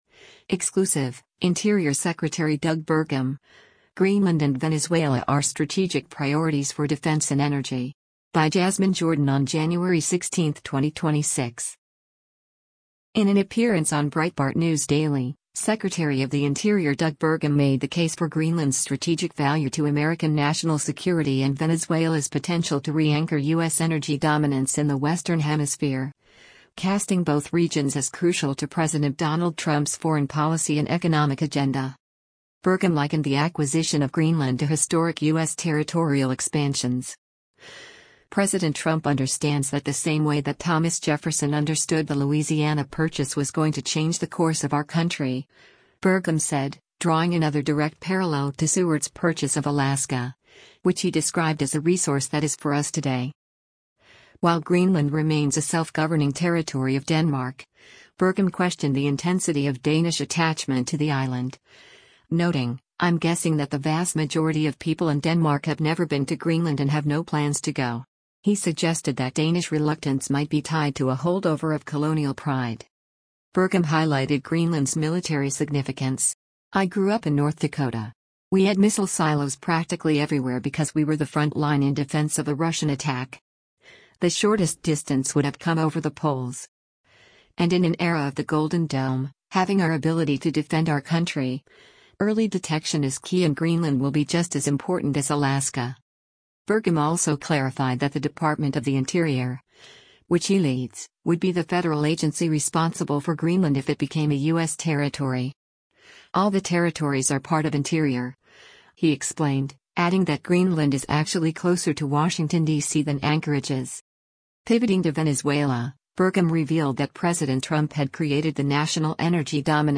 In an appearance on Breitbart News Daily, Secretary of the Interior Doug Burgum made the case for Greenland’s strategic value to American national security and Venezuela’s potential to re-anchor U.S. energy dominance in the Western Hemisphere, casting both regions as crucial to President Donald Trump’s foreign policy and economic agenda.